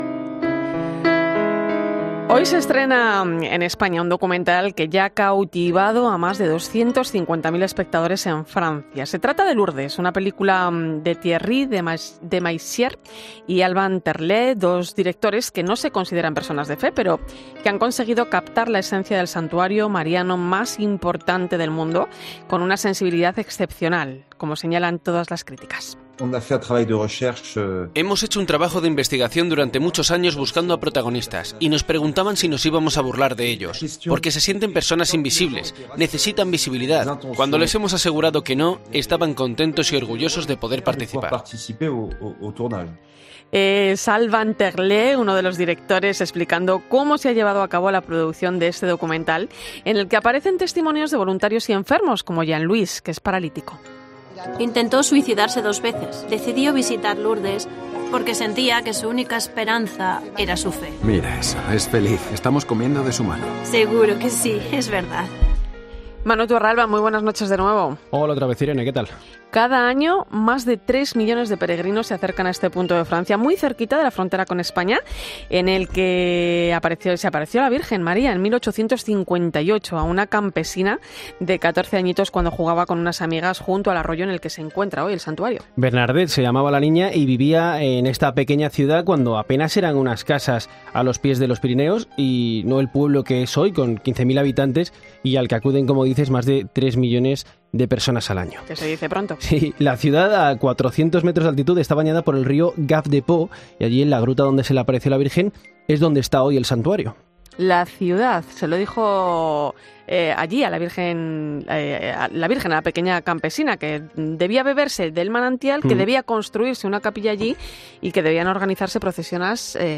y dos hospitalarias